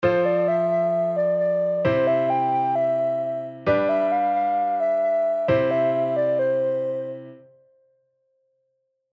短いフレーズを2種類作って、フレーズ1＋フレーズ2を繰り返すパターンです。
（コードはF⇒C⇒G⇒Cです）
上記の例でいうと、2小節目に対して、4小節目はリズムを少しだけ変えてあります。